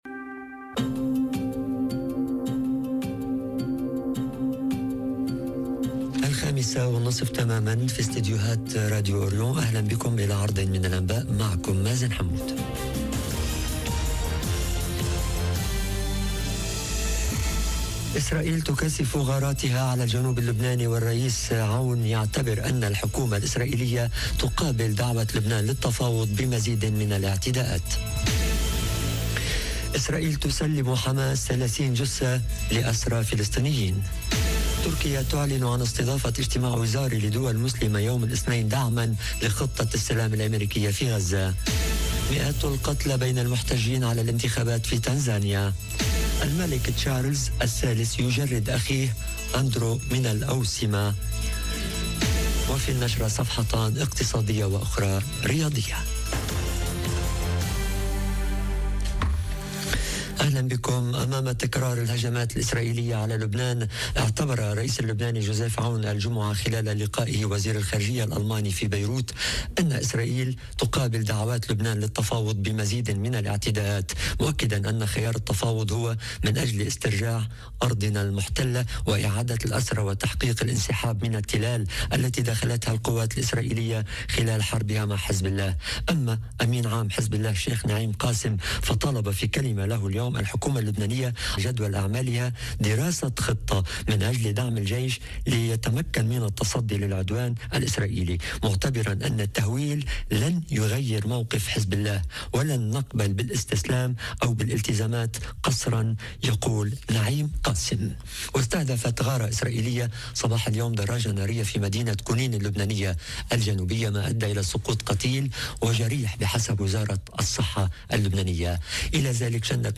نشرة الأخبار:إسرائيل تكثف غاراتها على الجنوب اللبناني وعون يعتبر ان الحكومة الإسرائيلية تقابل دعوة لبنان للتفاوض بمزيد من الاعتداءات - Radio ORIENT، إذاعة الشرق من باريس